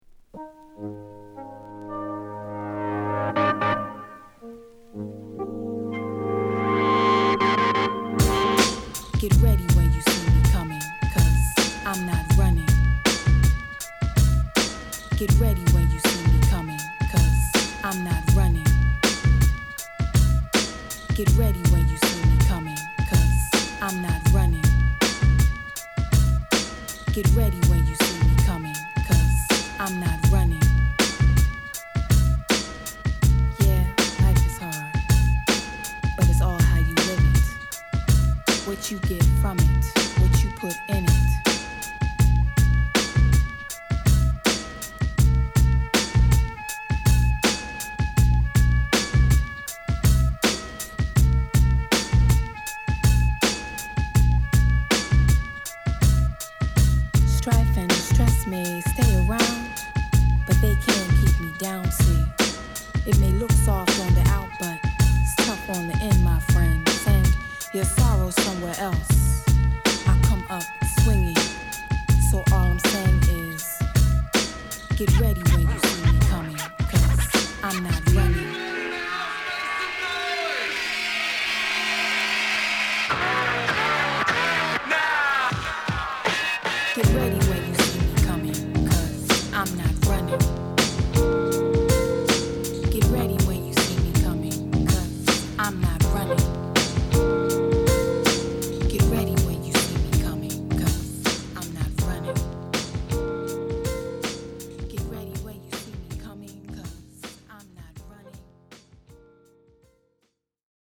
3. > JAZZY BREAK/ELECTRONICA/ABSTRACT